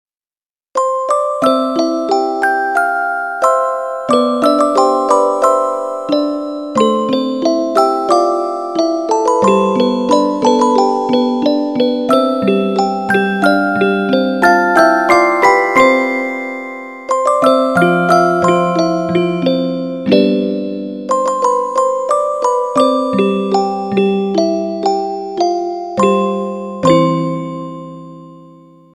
～～明るい・やさしい曲～～